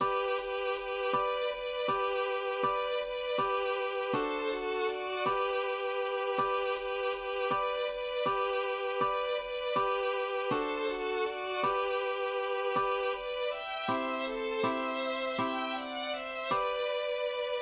\header{ dedication = "🏰029" title = "Ballade von den Pulethanern" subtitle = "Spottgesang aus dem Hartsteenschen" subsubtitle = "?" meter = "Jürgen S." arranger = "traditional" } myMusic = { << \chords { \germanChords \set chordChanges=##t \set Staff.midiInstrument="acoustic guitar (nylon)" \repeat segno 2 { \repeat volta 2 { g2.